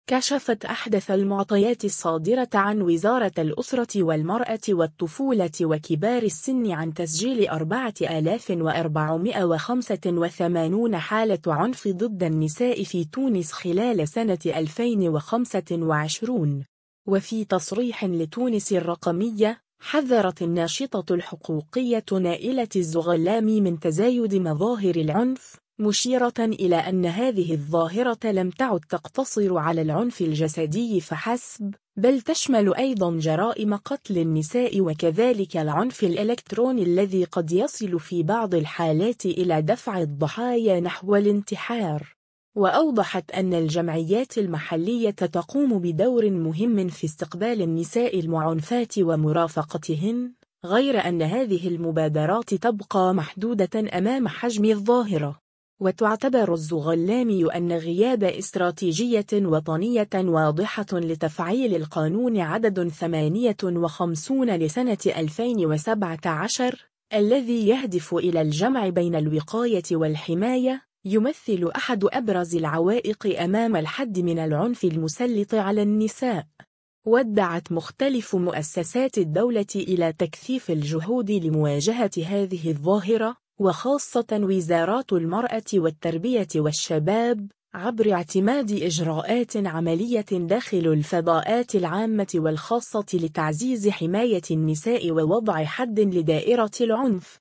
وفي تصريح لتونس الرقمية، حذرت الناشطة الحقوقية